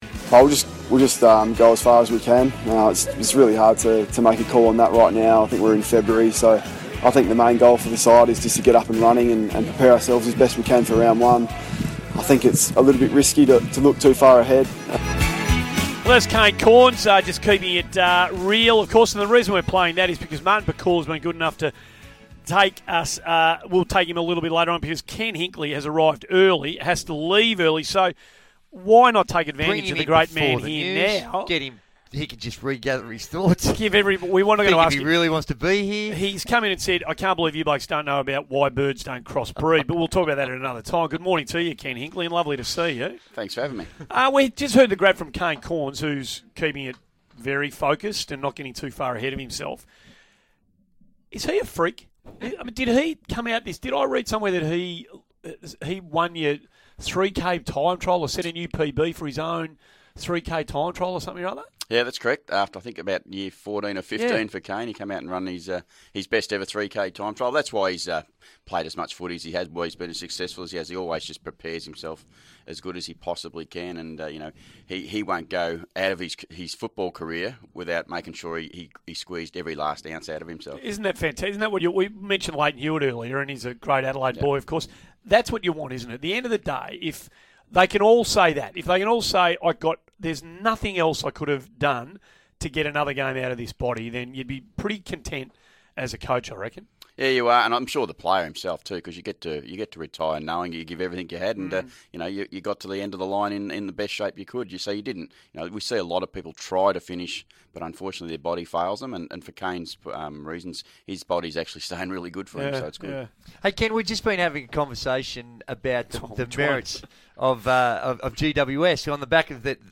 Ken Hinkley speaks to the Morning Glory crew on SEN radio